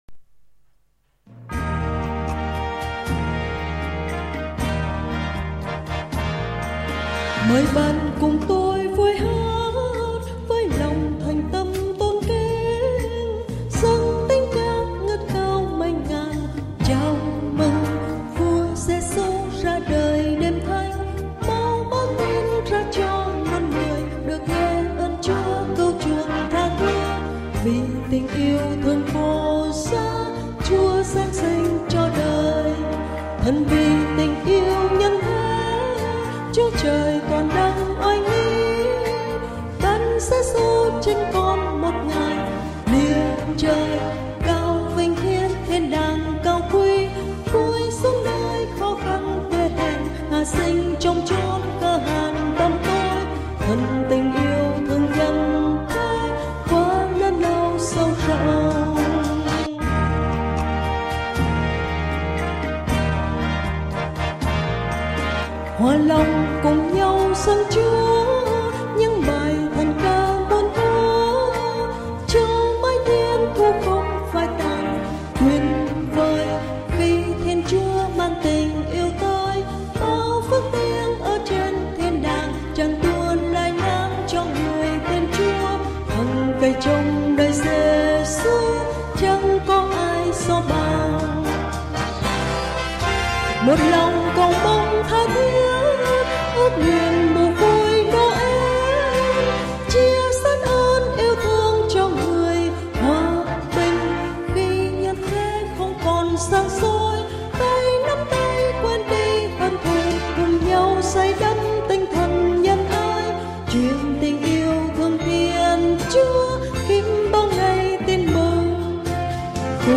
Bài hát Giáng sinh: VUI BÁO TIN MỪNG
VUI BÁO TIN MỪNG Cổ điệu Đức thế kỷ 14 Tôn vinh Chúa